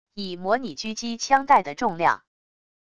以模拟狙击枪袋的重量wav音频